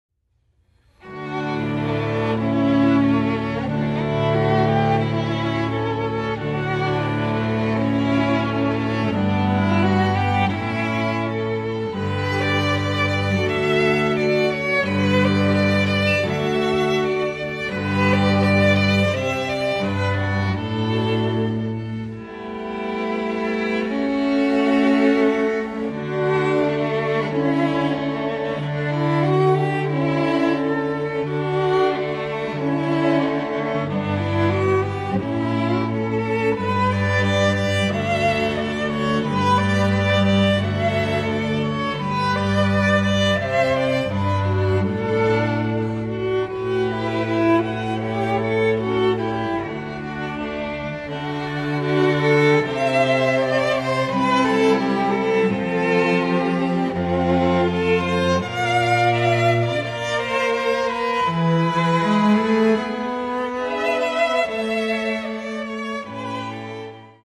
FLUTE QUARTET
(Flute, Violin, Viola and Cello)
(Two Violins, Viola and Cello)
MIDI